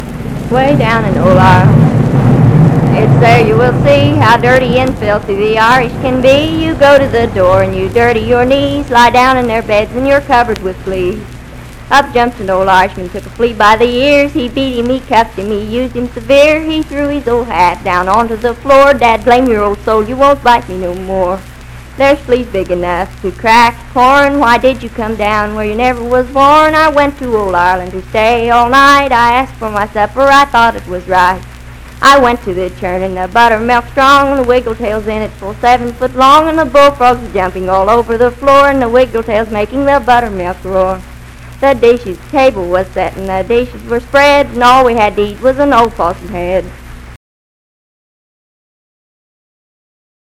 Unaccompanied vocal music
Ethnic Songs, Humor and Nonsense
Voice (sung)
Parkersburg (W. Va.), Wood County (W. Va.)